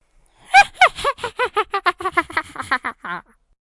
女孩的笑声
这是相当平静，也许有点令人毛骨悚然，可以用于谈话，假鬼脸或令人毛骨悚然的小恐怖电影在黑暗中的声音如果修改。
Tag: 轻笑 声音 女孩 女性 女人 傻笑